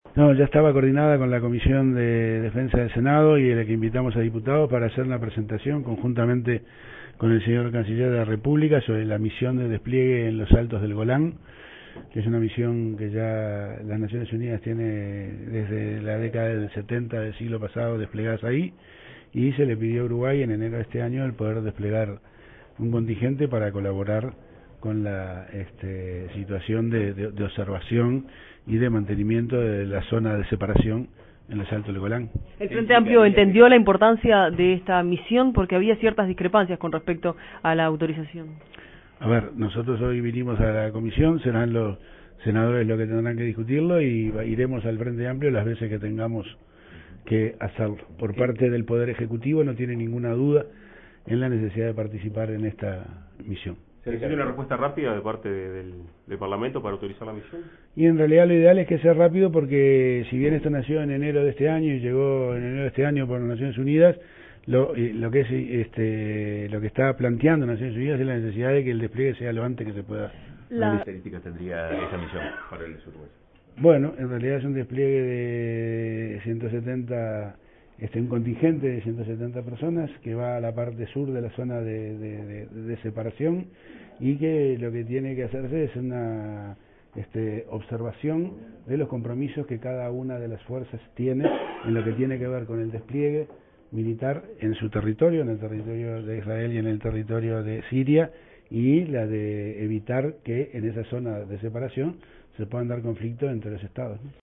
El ministro de Defensa, José Bayardi, realizó este lunes 6 junto al canciller Rodolfo Nin Novoa una presentación en la Comisión de Defensa del Senado sobre la misión en Altos del Golán. “El Ejecutivo no tiene ninguna duda sobre la necesidad de participar en esta misión”, sostuvo el jerarca, en alusión a la solicitud realizada por Naciones Unidas en enero de este año.